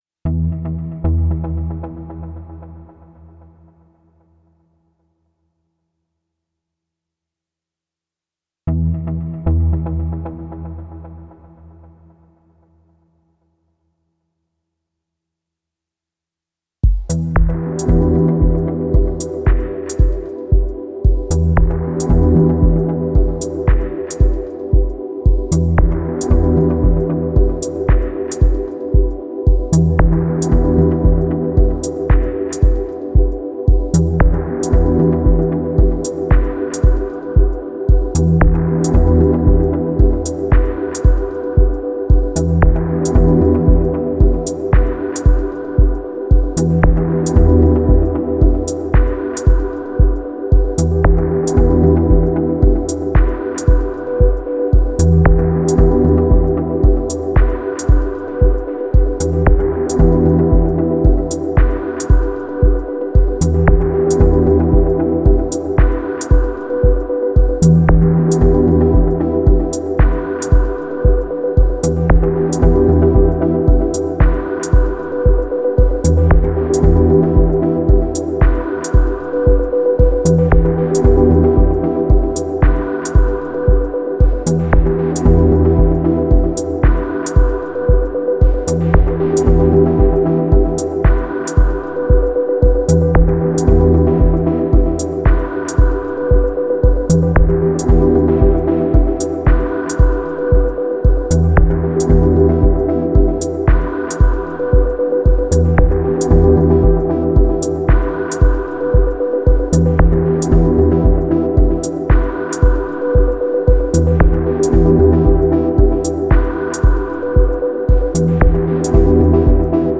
Genre: Ambient/Dub Techno/Deep Techno.